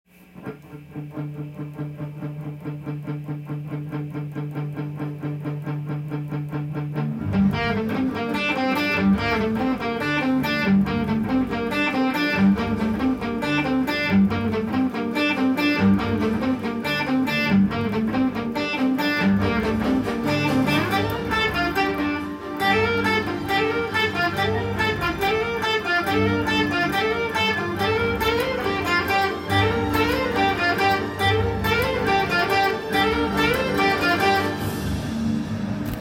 音源にあわせて譜面通り弾いてみました
マイナー系のスピード感あふれるメロディーが細かい曲です。
エレキギターで弾く時、
４段目からは、レガート奏法と言われるハンマリングとプリングの
繰り返しの速弾きが登場します。